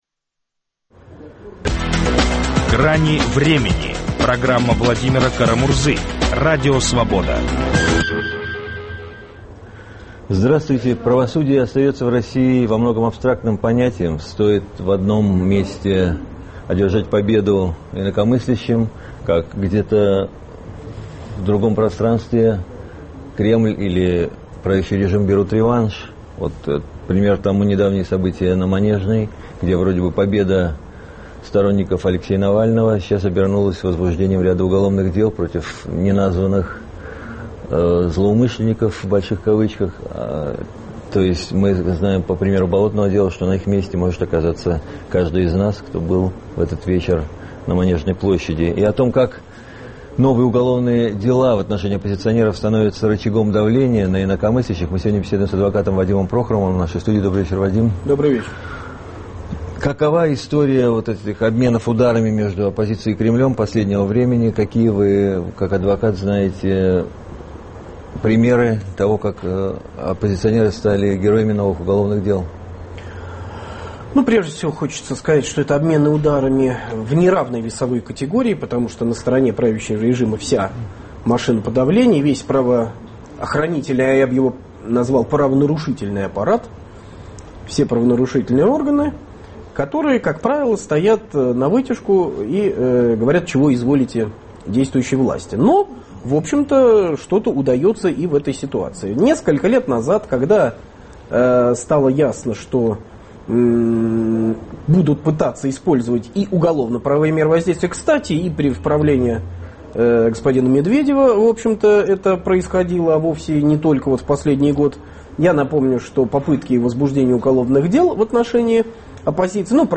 Новые уголовные дела против оппозиционеров - рычаг давления на неугодных. Об этом беседуем с адвокатом Вадимом Прохоровым и политологом Дмитрием Орешкиным.